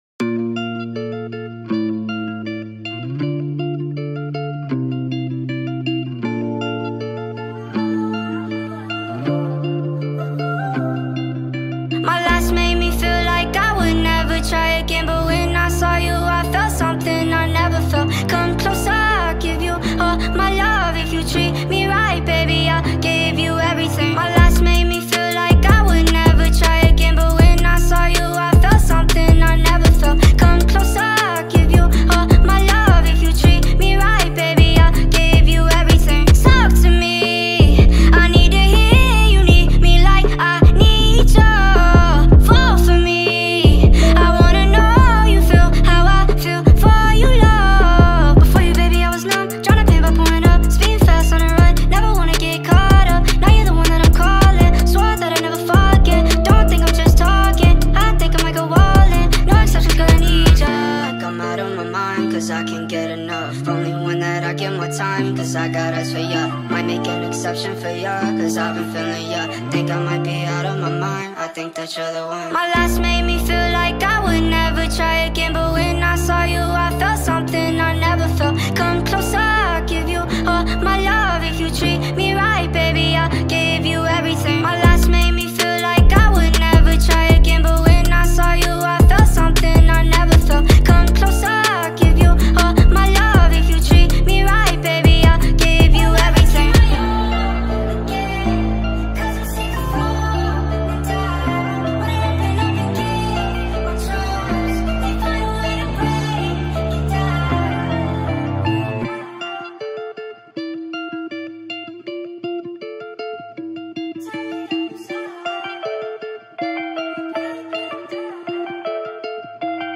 عاشقانه
غمگین